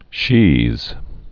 (shēz)